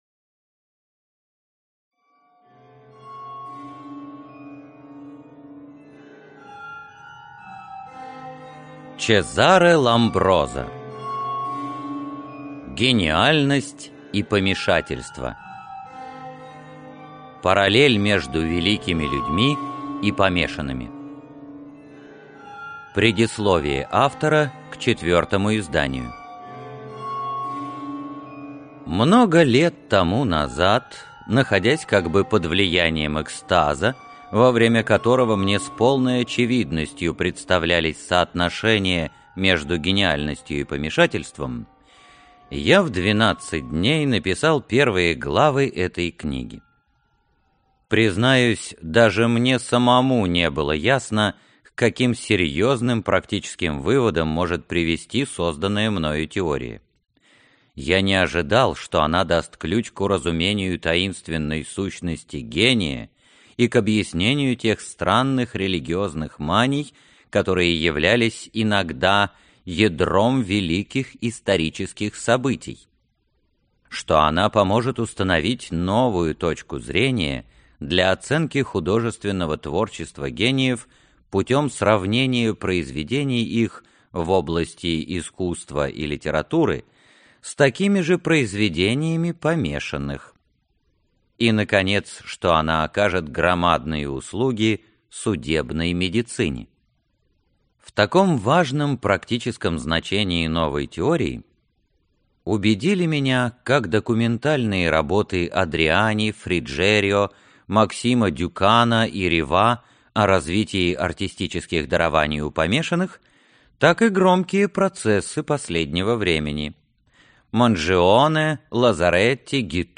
Аудиокнига Гениальность и помешательство | Библиотека аудиокниг